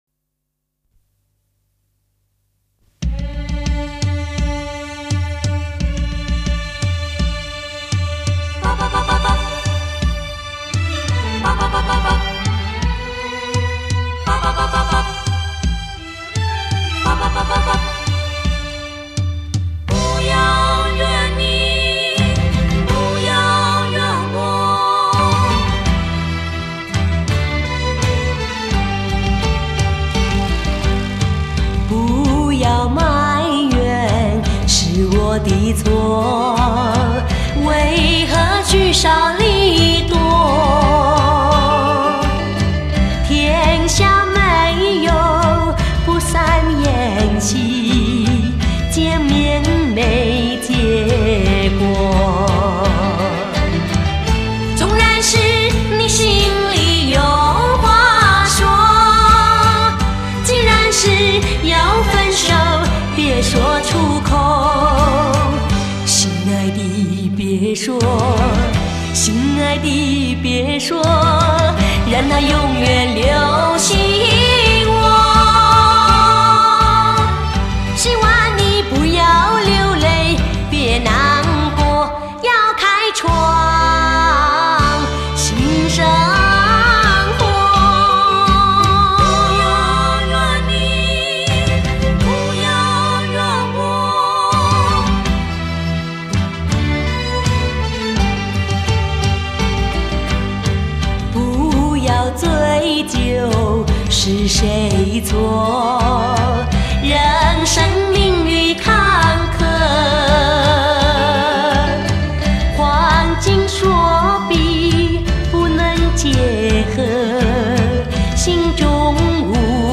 这是一张福建译曲专辑。旋律简单，歌词直接。